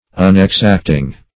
unexacting - definition of unexacting - synonyms, pronunciation, spelling from Free Dictionary